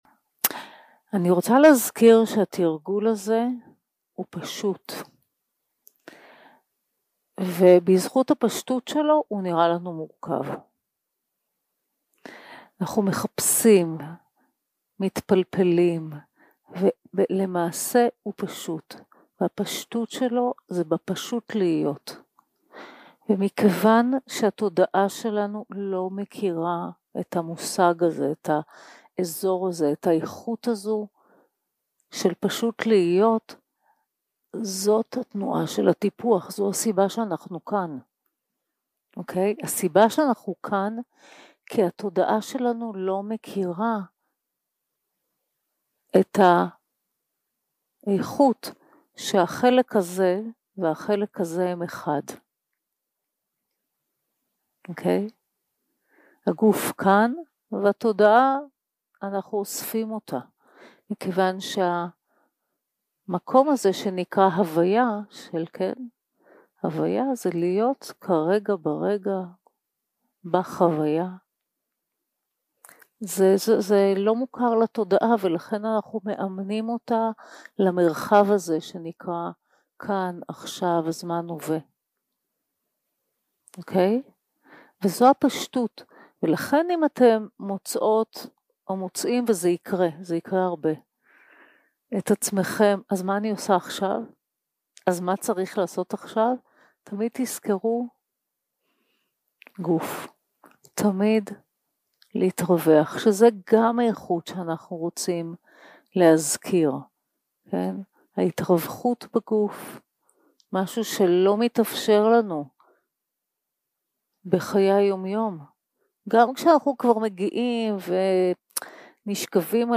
יום 1 - הקלטה 1 - ערב - מדיטציה מונחית
Dharma type: Guided meditation